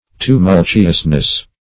tumultuousness - definition of tumultuousness - synonyms, pronunciation, spelling from Free Dictionary
Tu*mul"tu*ous*ness, n.
tumultuousness.mp3